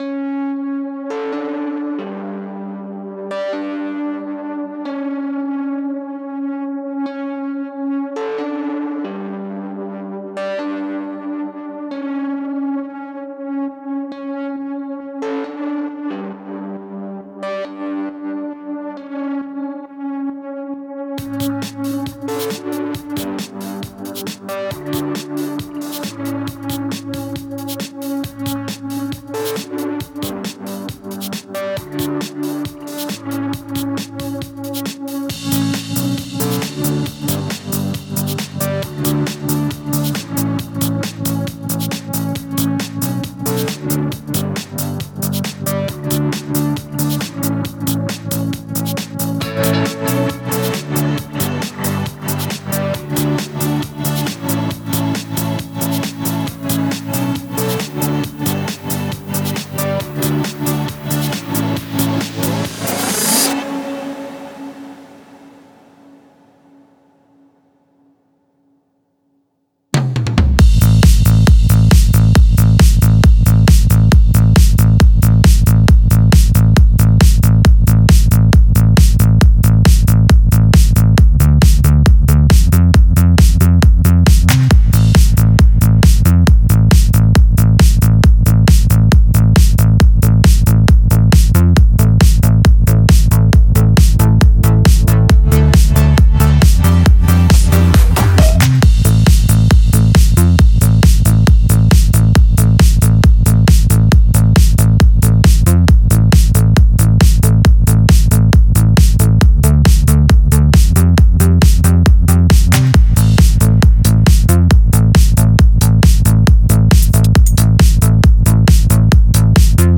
Назад в Progressive Trance
Style: Psy-Prog
Quality: 320 kbps / Stereo